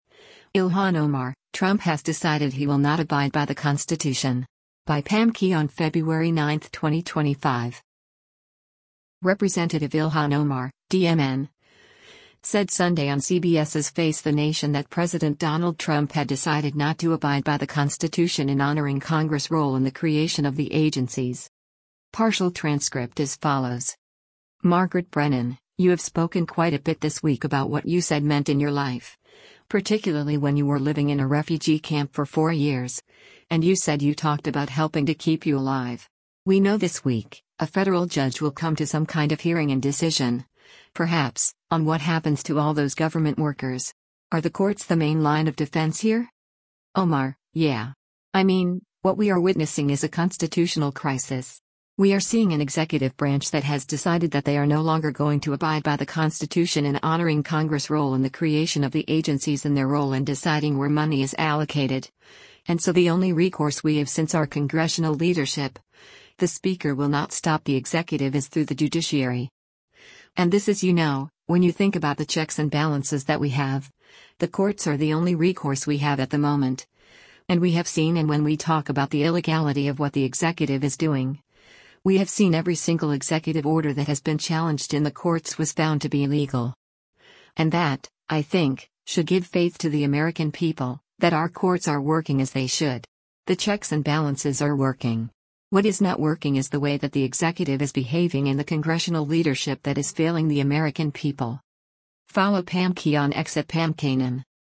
Representative Ilhan Omar (D-MN) said Sunday on CBS’s “Face the Nation” that President Donald Trump had decided not to “abide by the Constitution in honoring Congress’ role in the creation of the agencies.”